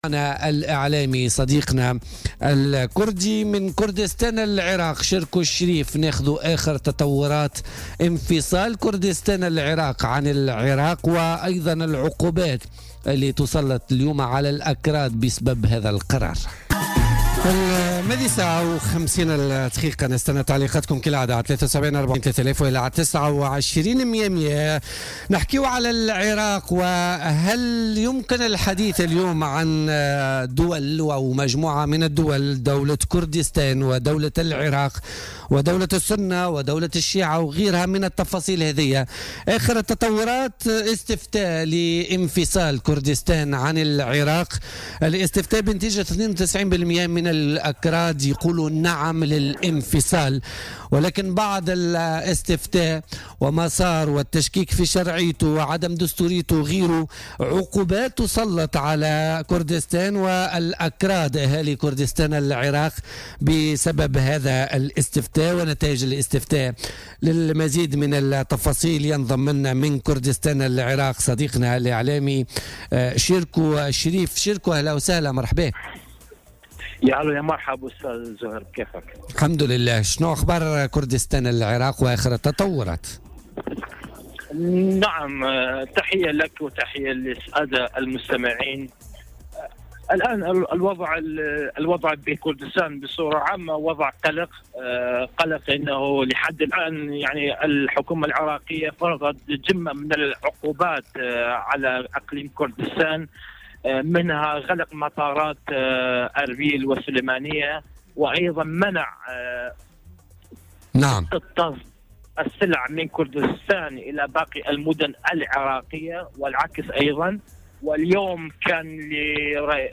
التفاصيل مع صحفي كردي